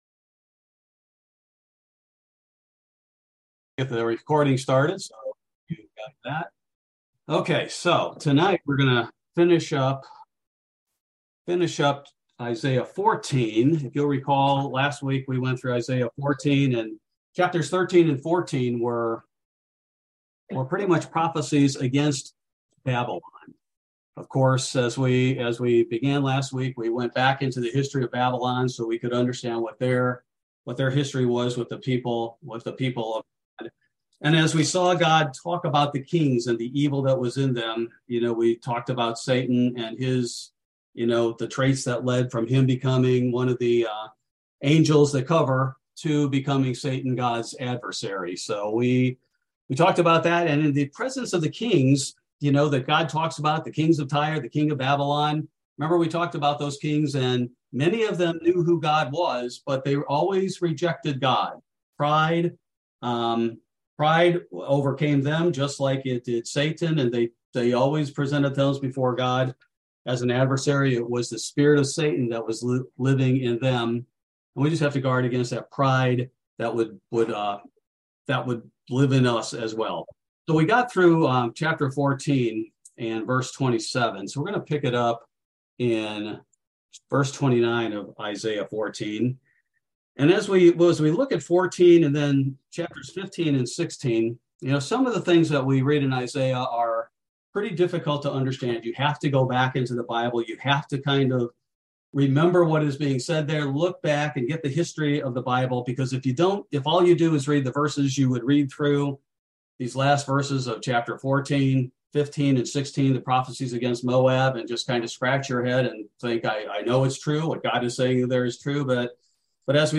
Bible Study: November 9, 2022